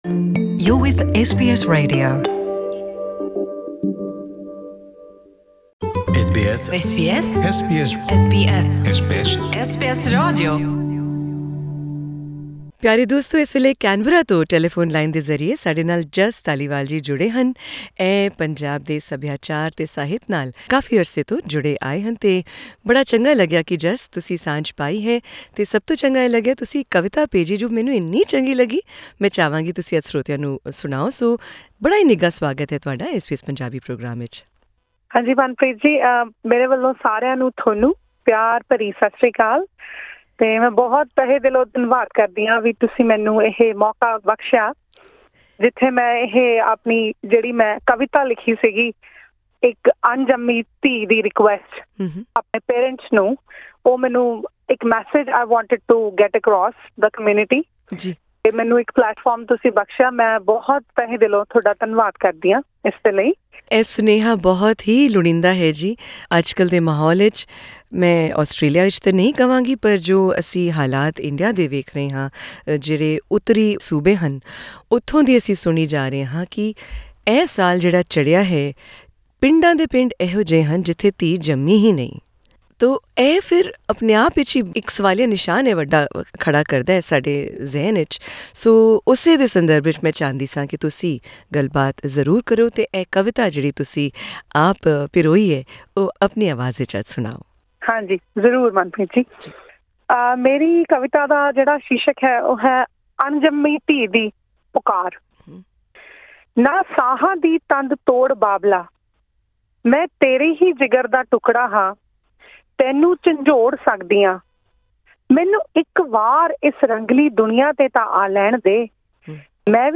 Click on the audio link above to hear the poem, as presented by it's author.